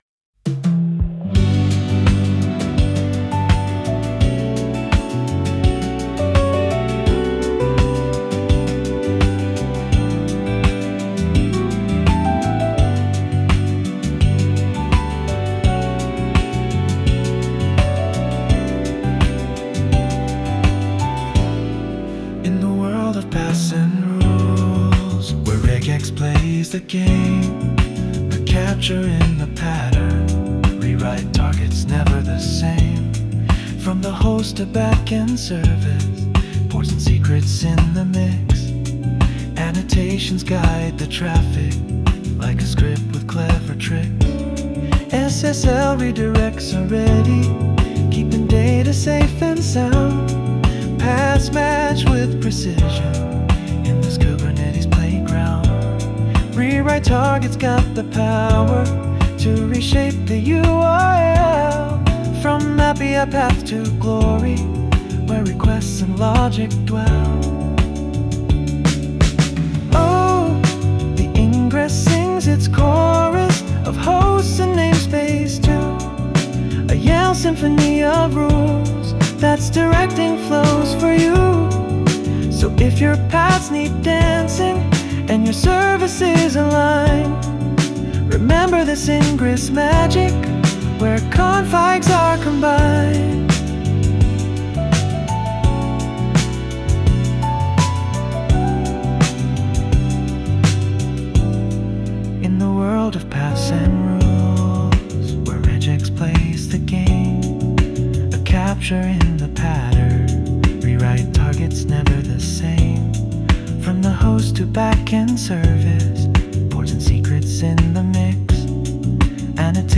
この記事を歌う